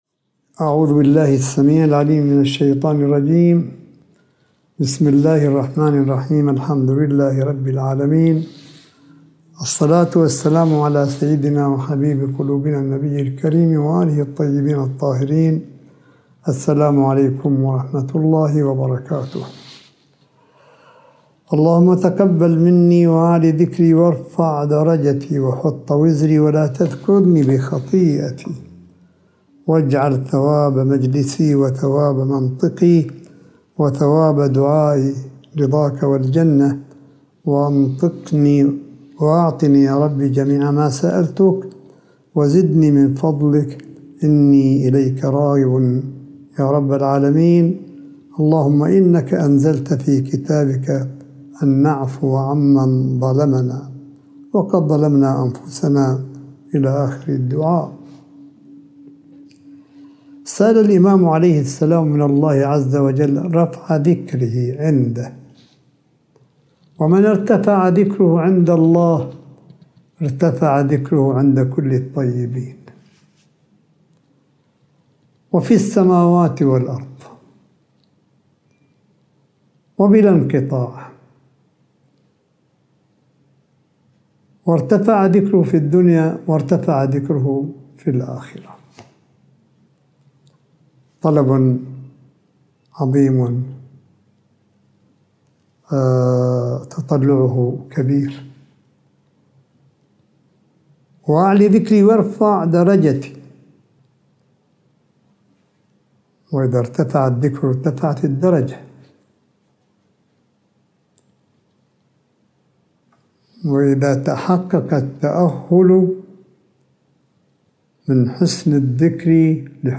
ملف صوتي للحديث الرمضاني (30) لسماحة آية الله الشيخ عيسى أحمد قاسم حفظه الله – 13 مايو 2021م